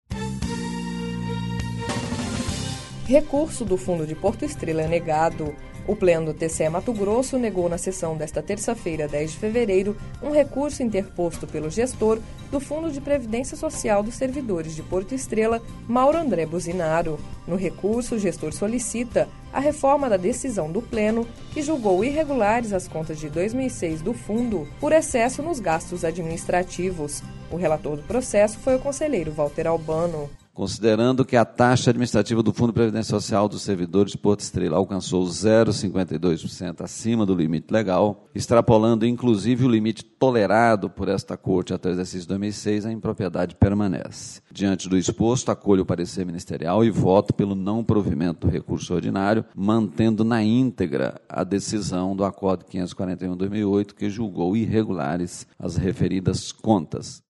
Sonora: Valter Albano – conselheiro do TCE-MT